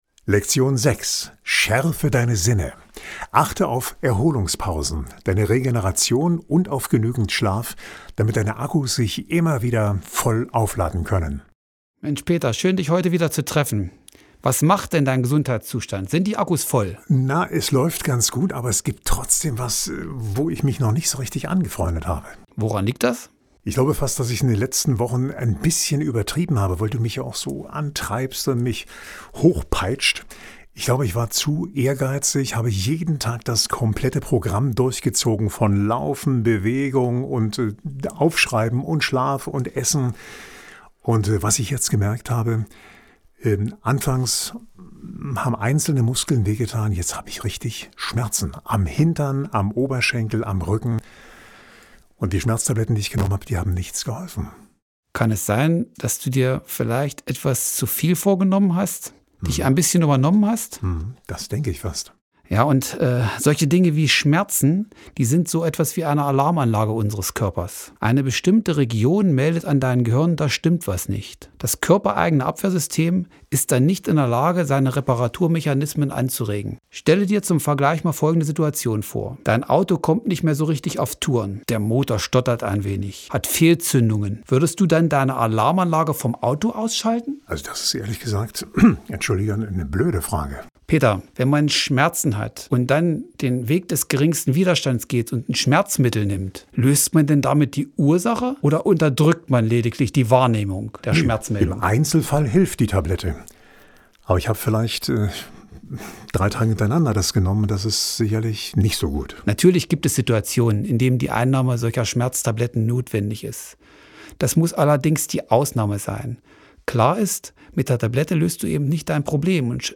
23.03.2022, 17:00 Uhr : Diese Podcast Serie ist ein strukturierter, aufeinander aufbauender Wissensworkout, in dem Sie sich ihre persönlichen Ressourcen für ein starkes Immunsystem erarbeiten können. Wir präsentieren Ihnen hier über zwölf Wochen den Dialog zwischen dem Fernsehjournalisten Peter Escher und dem Nordhäuser Sportwissenschaftler und Buchautor